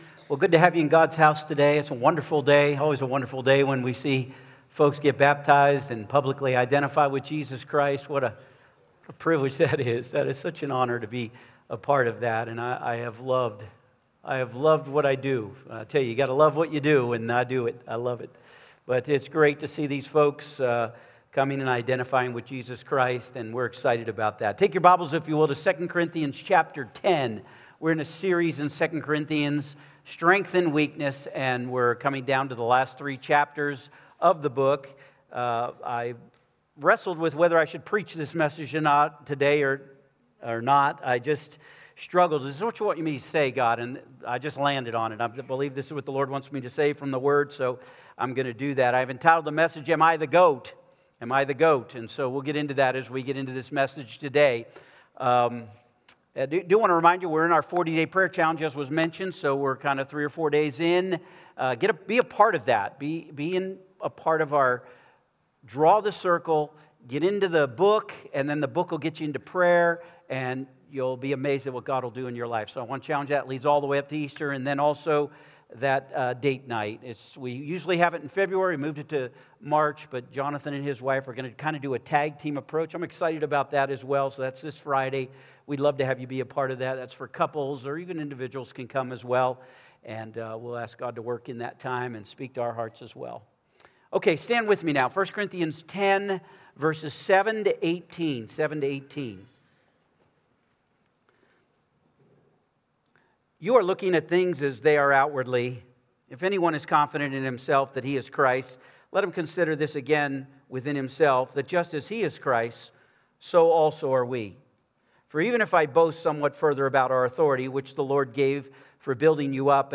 Sermons
Sermon Archive